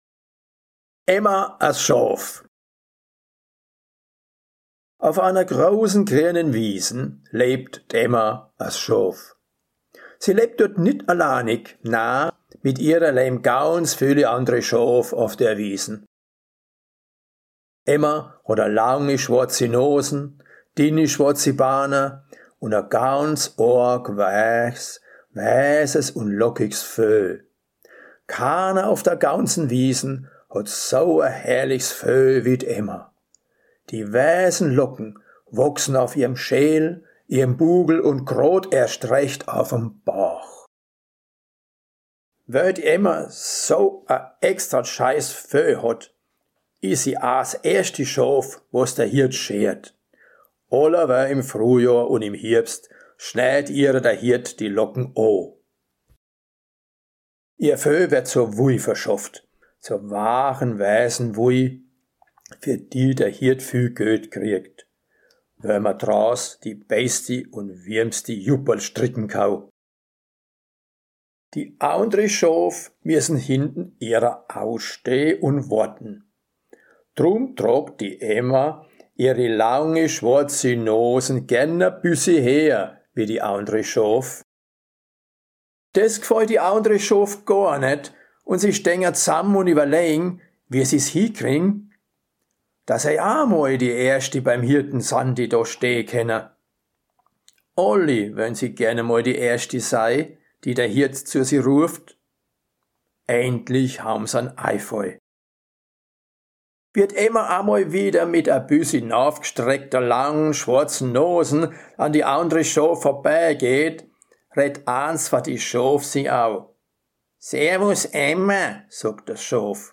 Emma, das Schaf - Dialekt Ungardeutsch
auch Mittelbayrischer Dialekt Ofener Bergland So hört sich die Geschichte in Ungardeutsch an: